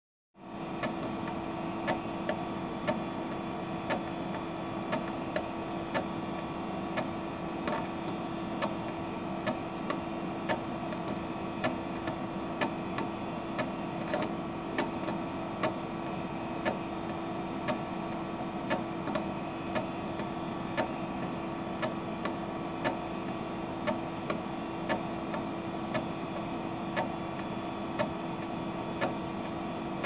Repetitive Hard Drive Seek under IRIX
Just set up my O2 with a fresh install of IRIX 6.5.30 and a Compaq SCA drive taken from an old server of mine, and I've been experiencing this constant repetitive disk activity even when idle. It's almost exactly every second it makes two clicks out of the hard drive.
Irix_disk.mp3